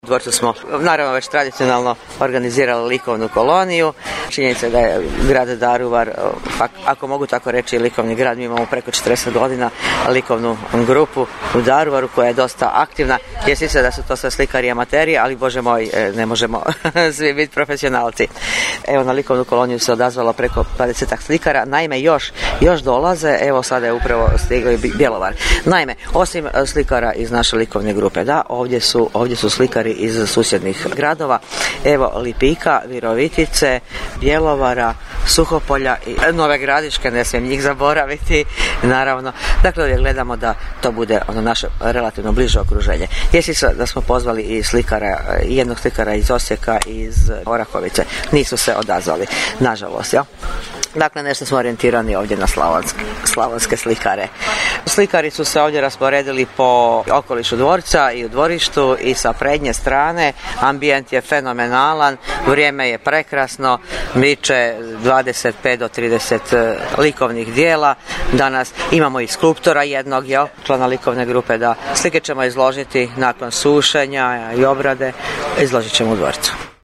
U nastavku Vijesti objavljujemo tonsku ilustraciju koja može dočarati djelić atmosfere sinoćnjeg programa u Pučkoj knjižnici. Poslušajte kako se u ulozi čitačice snalazi zamjenica gradonačelnika Veronika Pilat